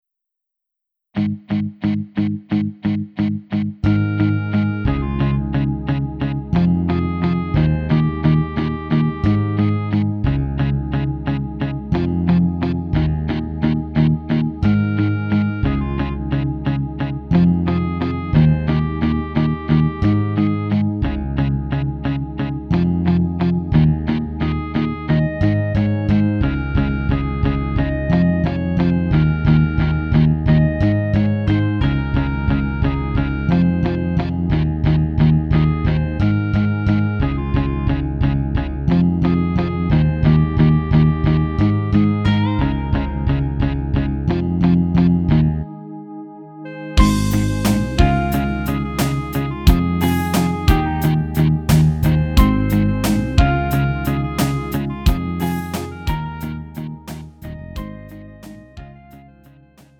음정 원키 3:35
장르 구분 Lite MR